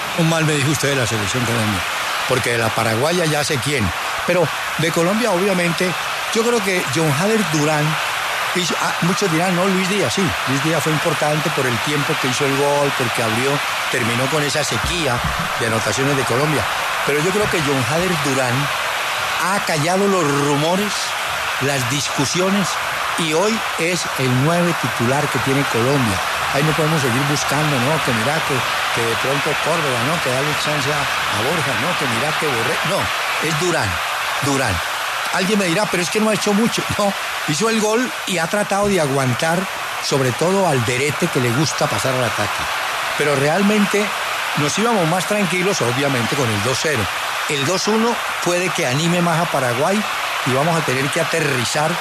El reconocido periodista deportivo Hernán Peláez analizó en los micrófonos de W Radio el primer tiempo de la Selección Colombia ante Paraguay por la jornada 14 de las Eliminatorias Sudamericanas rumbo al Mundial de Estados Unidos, México y Canadá 2026.